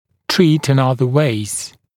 [triːt ɪn ‘ʌðə weɪz][три:т ин ‘азэ уэйз]лечить другими способами